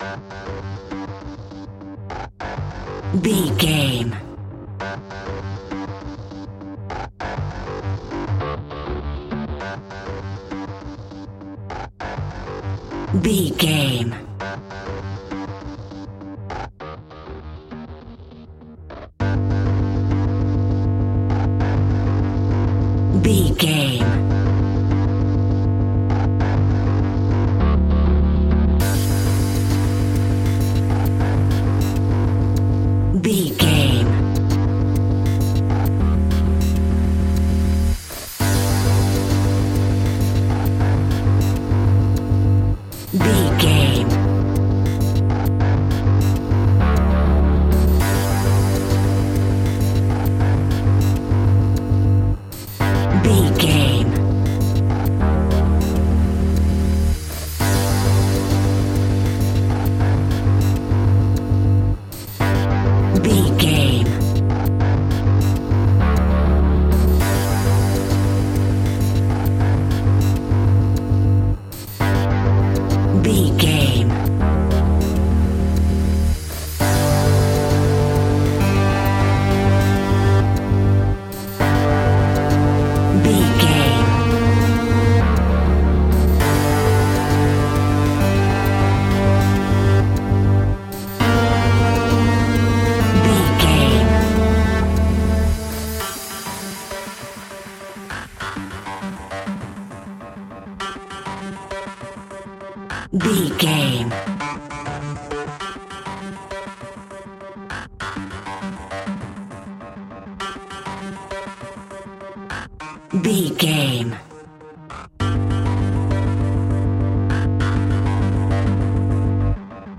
Aeolian/Minor
ominous
dark
haunting
eerie
synthesiser
percussion
drums
bass guitar
strings
electronic music